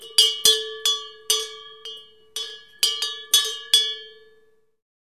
На этой странице собраны звуки коровьего колокольчика — натуральные и атмосферные записи, которые перенесут вас на деревенское пастбище.
Звук дребезжащего колокольчика на корове